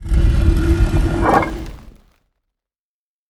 sounds / doors / stone / open.ogg